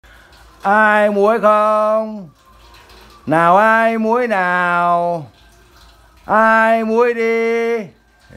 Tiếng Rao Bán Muối mp3